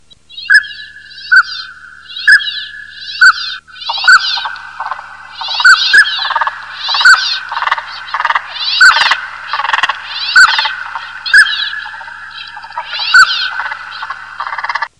Звуки манка
Утка шилохвост птица